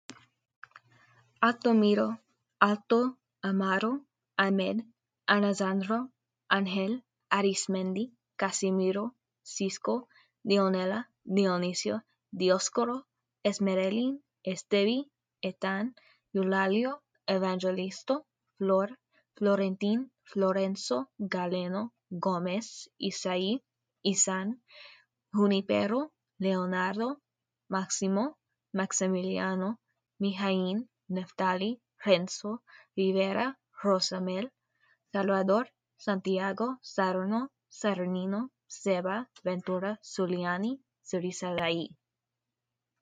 So many beautiful names and your voice just makes them sing :notes: Thank you so much for including the pronunciation audio too.